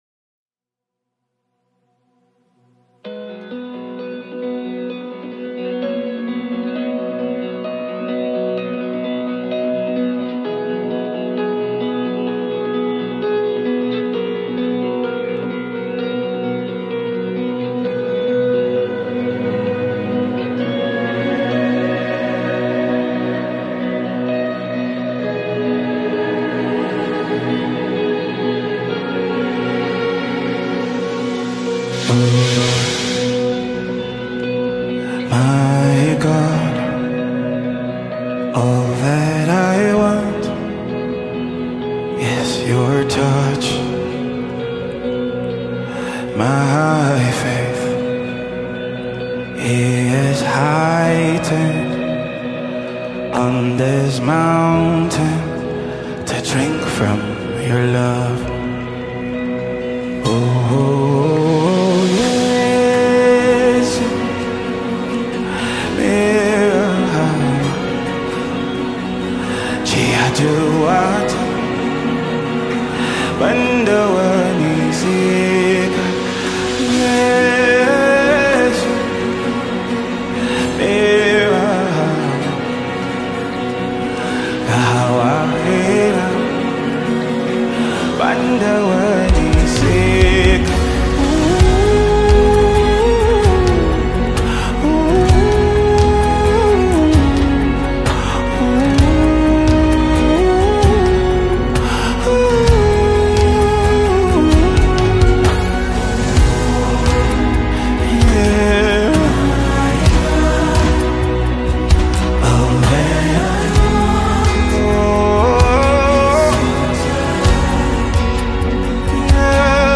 Nigerian gospel
live recorded song
was recorded live